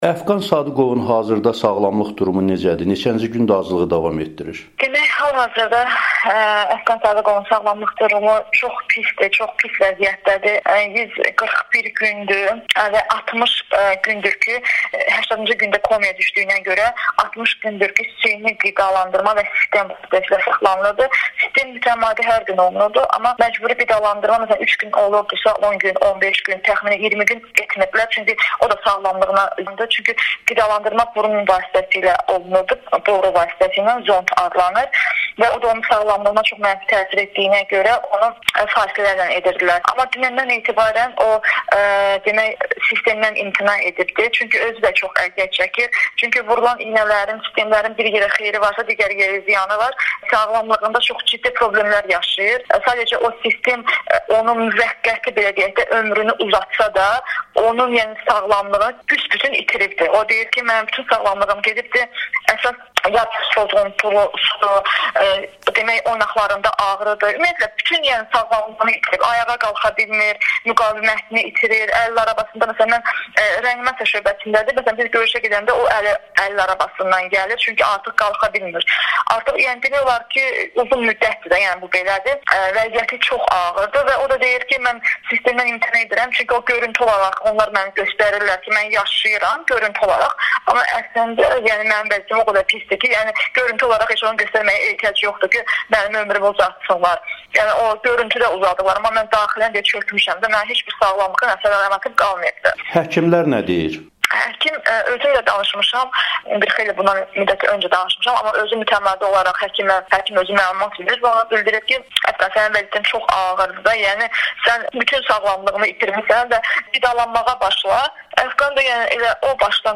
müsahibəsində göz yaşları içərisində deyib.